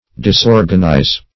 Disorganize \Dis*or"gan*ize\, v. t. [imp.